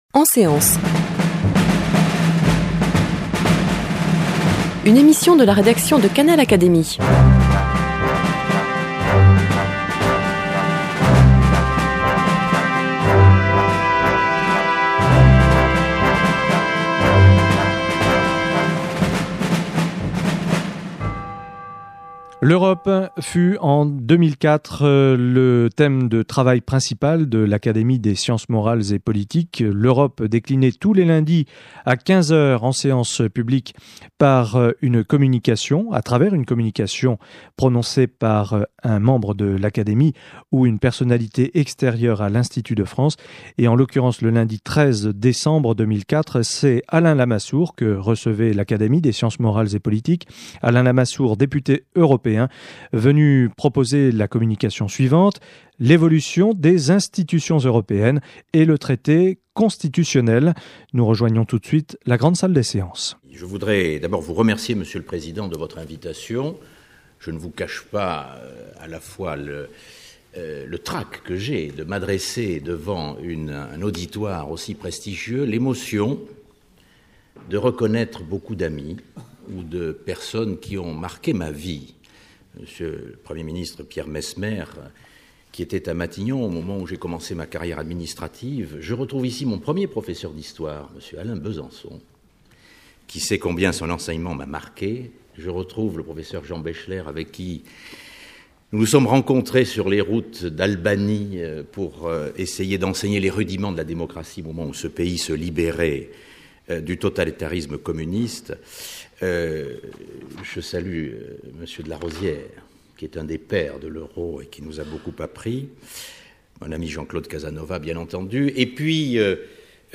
Communication prononcée par Alain Lamassoure, député européen, devant l’Académie des Sciences morales et politiques le lundi 13 décembre 2004.